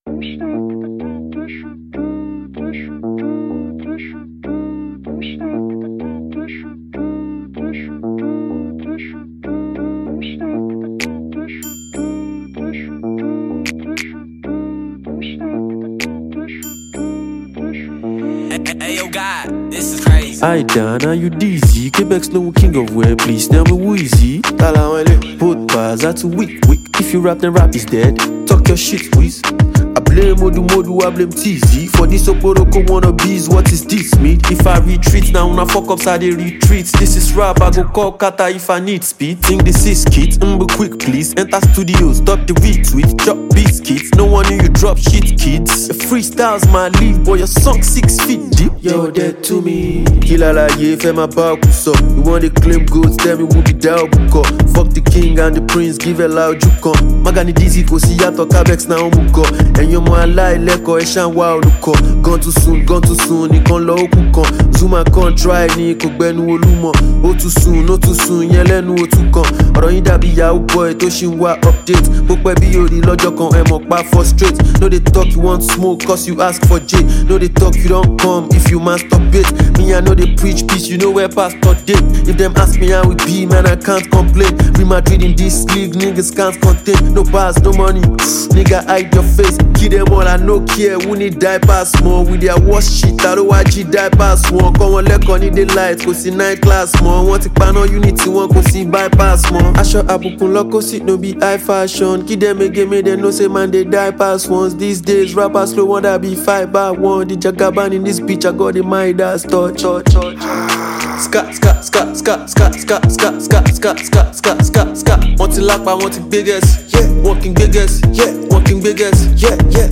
Talented Nigerian Rapper